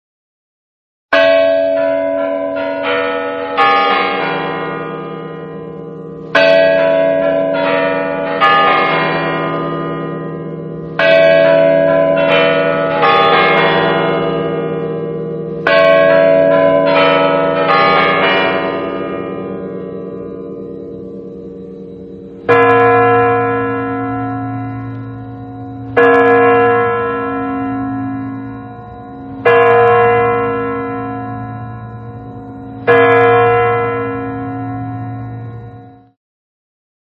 A batalha dos sinos do Kremlin - sinos
• Categoria: Sons de carrilhão
• Qualidade: Alto